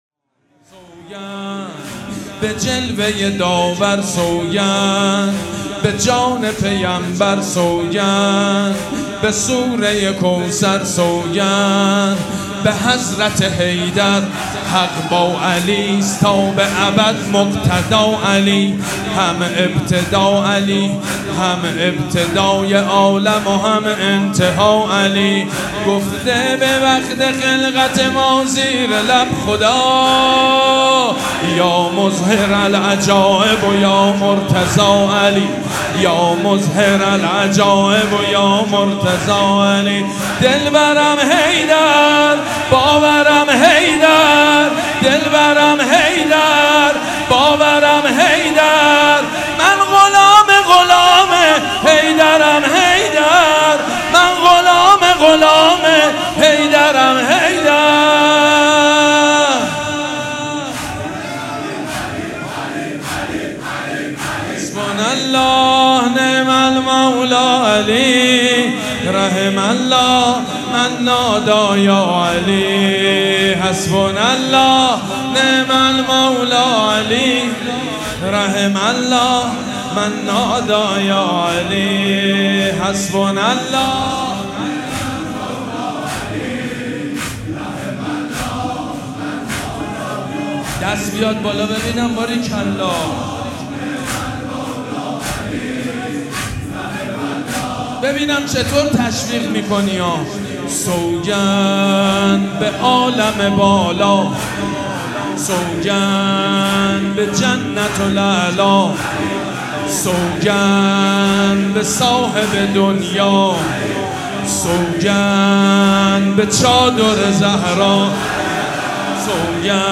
حسینیه ریحانة‌الحسین (سلام‌الله‌علیها)
مداح
حاج سید مجید بنی فاطمه